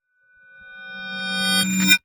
time_warp_reverse_spell_01.wav